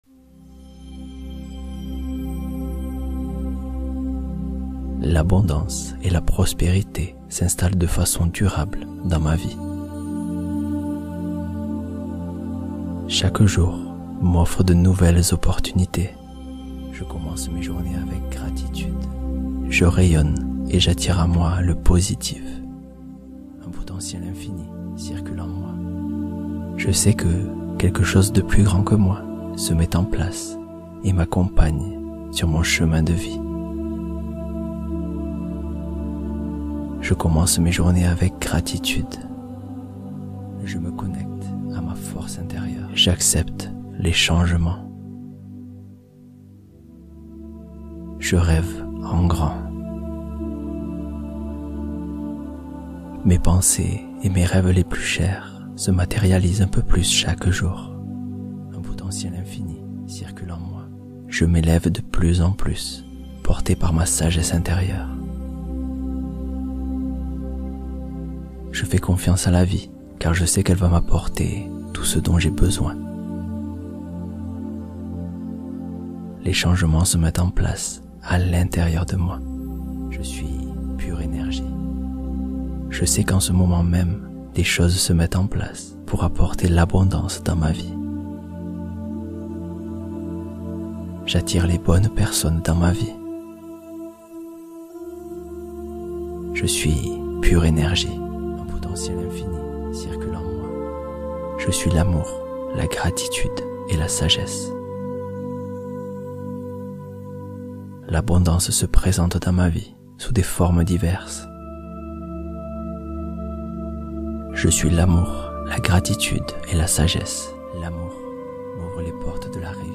Apaiser l’âme : méditation profonde contre anxiété et dépression